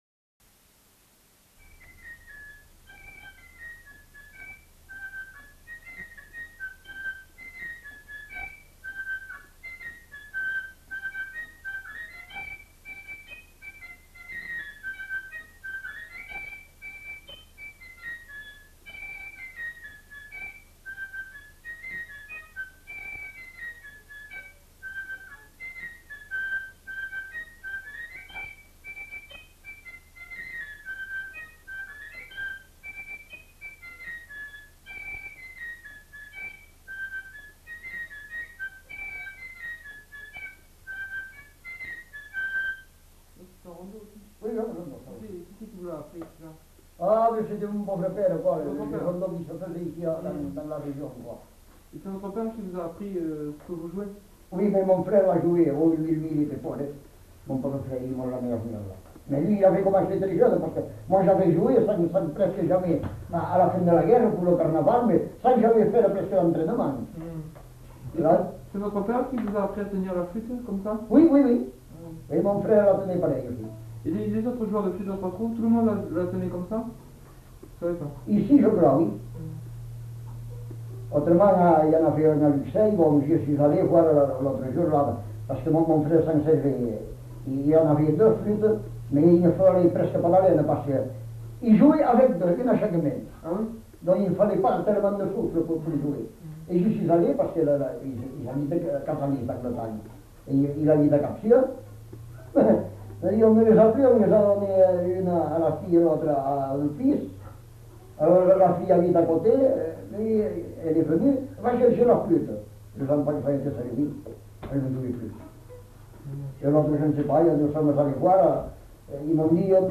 Répertoire d'airs à danser joué à la flûte à trois trous et à l'harmonica
enquêtes sonores
Rondeau